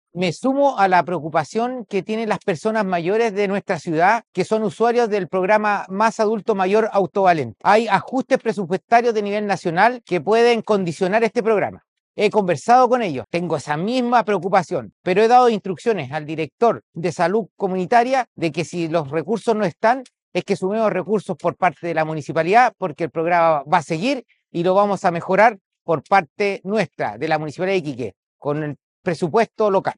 Frente a la protesta, el alcalde Mauricio Soria Macchiavello salió a dialogar con los asistentes y aseguró que comparte la preocupación por el futuro del programa.